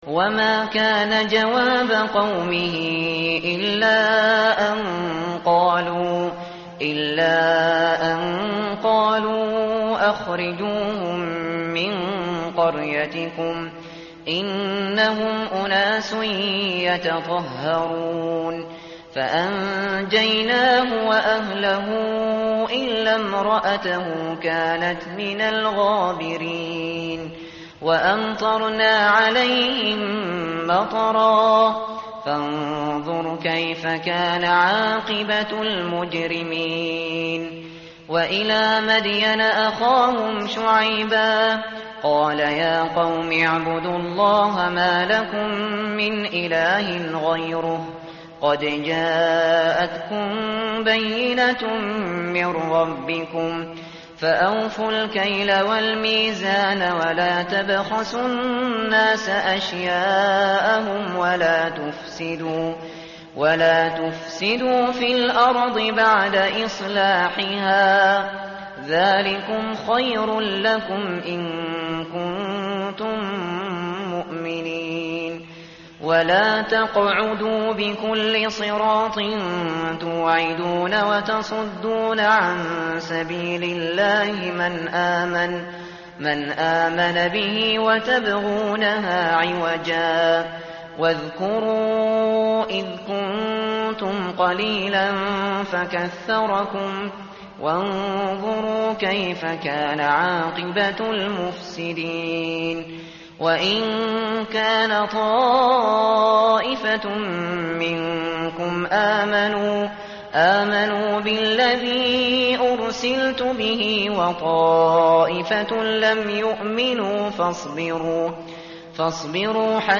tartil_shateri_page_161.mp3